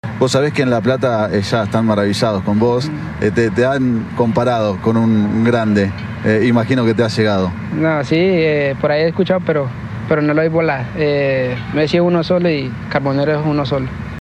(Johan Carbonero en entrevista con TNT Sports)